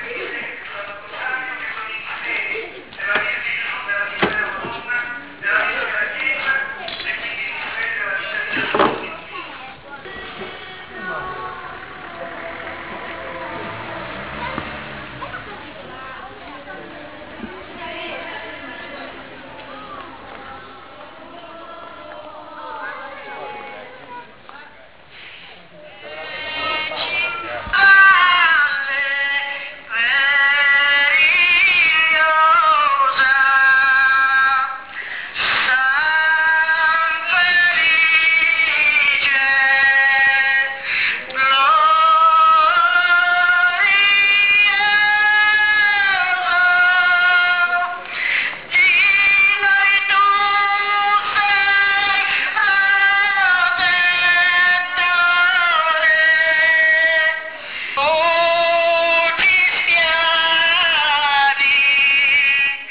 procession3_test.wav